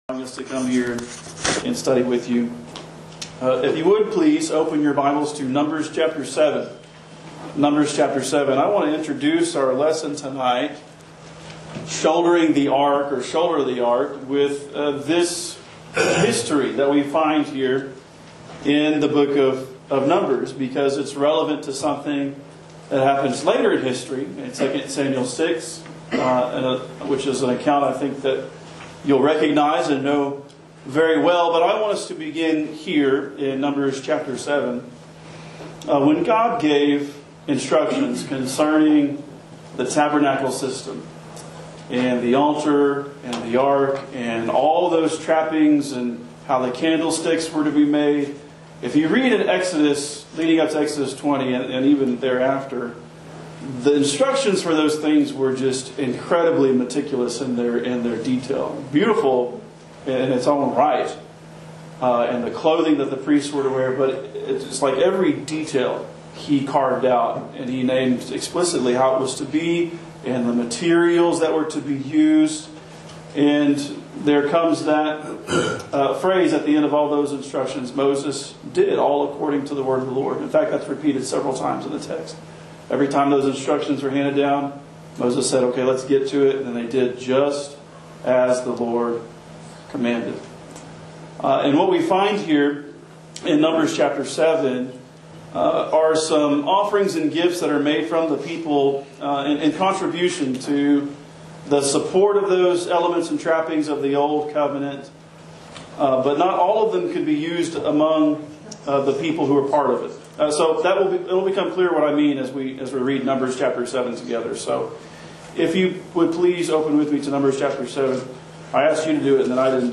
Shoulder The Ark- Gospel meeting